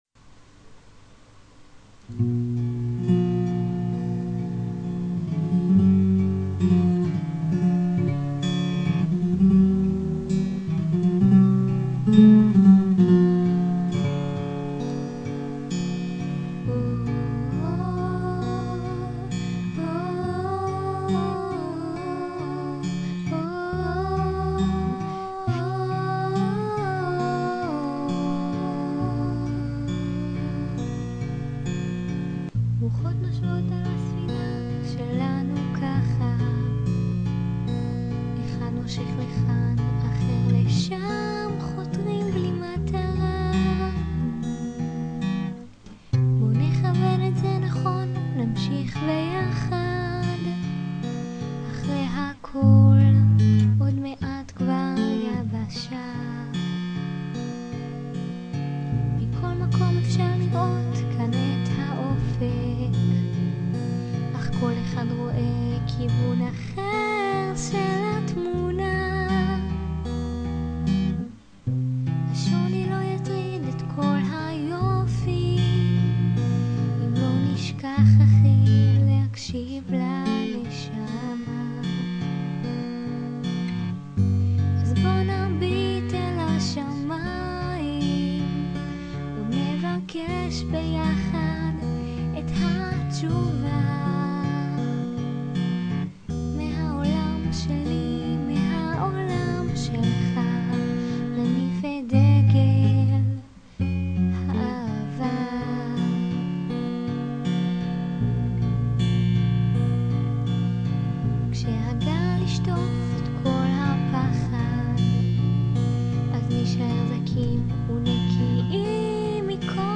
עדין במיוחד ויפהיפה!!!!!!!
קול עדין וערב.
אהבתי במיוחד את הסוף, ההוספה של עוד קול..:)